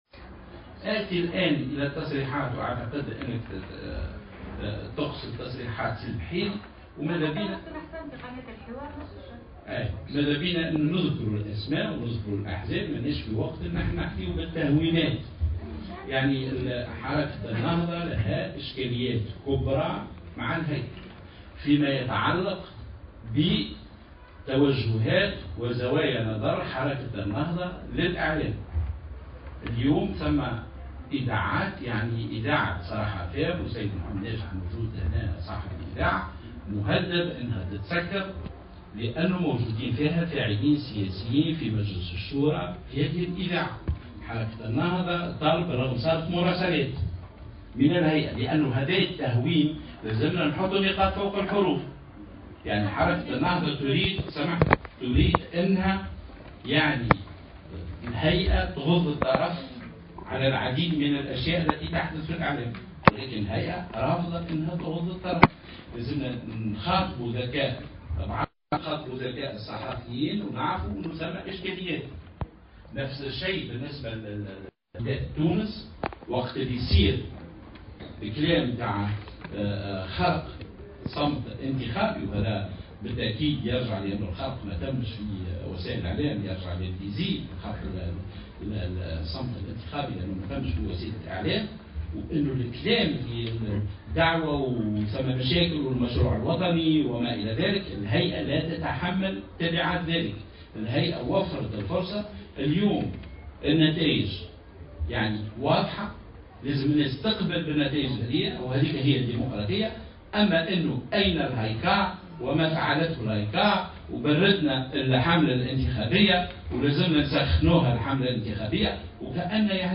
أكد عضو الهيئة العليا المستقلة للاتصال السمعي البصري هشام السنوسي في ندوة صحفية عقدت اليوم بالعاصمة إن حركة النهضة لها اشكاليات كبرى مع الهايكا في ما يتعلق باستغلالها للاعلام.